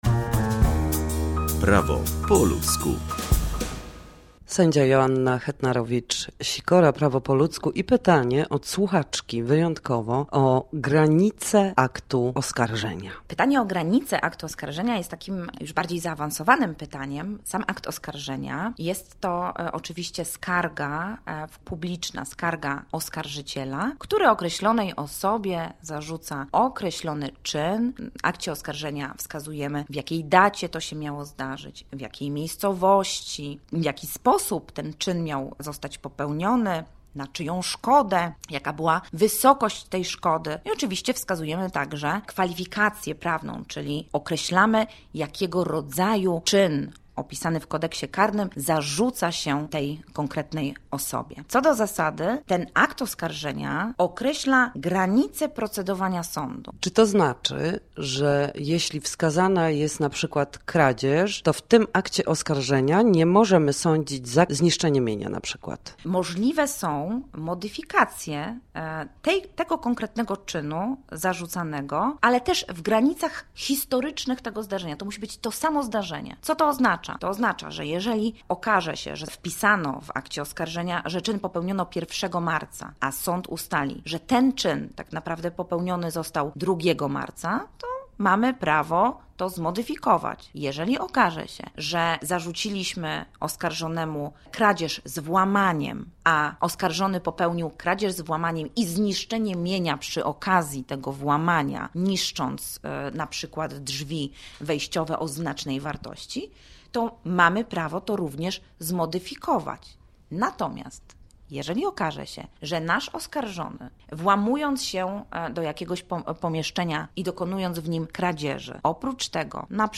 Gościem dzisiejszej porannej audycji była sędzia Joanna Hetnarowicz-Sikora, która odpowiadała na pytania słuchaczki: Jakie są granice aktu oskarżenia i czy można w nim uwzględnić kilka wykroczeń naraz?